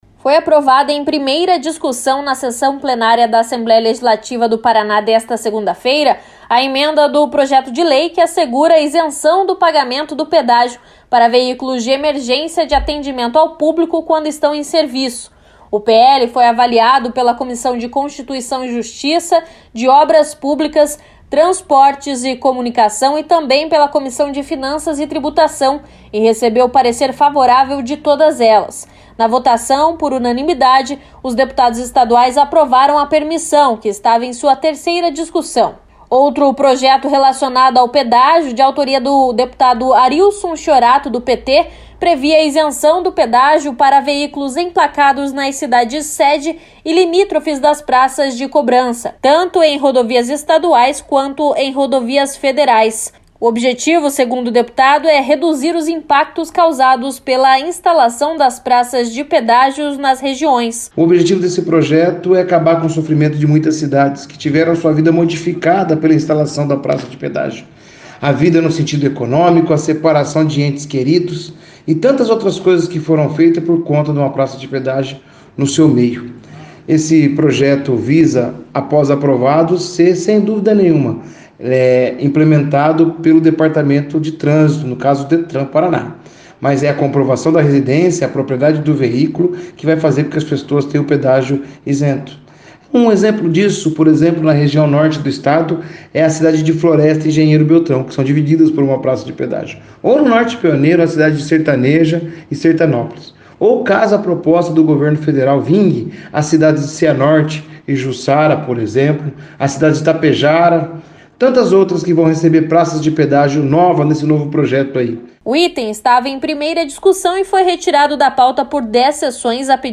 Os deputados estaduais do Paraná apreciaram dois projetos nesta segunda-feira sobre a isenção do pagamento das tarifas de pedágios para veículos de emergência e também para aqueles emplacados na região das praças. Confira na reportagem como foi a discussão.